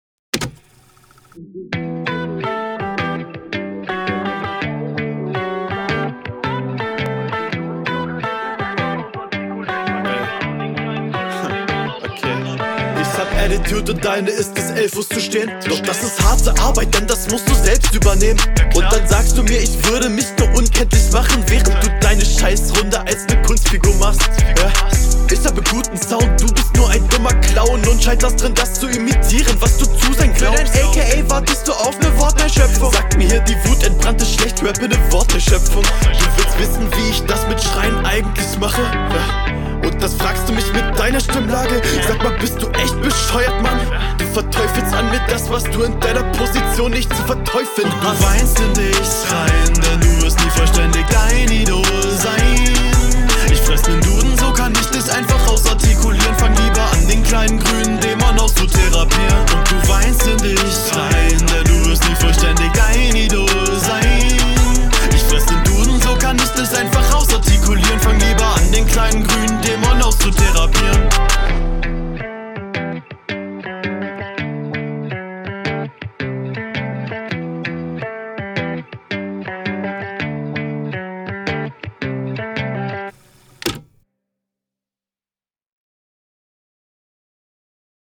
Mag den Kassetten-Recorder Sound am Anfang und diese Vorwegspielchen der Hook im Hintergrund.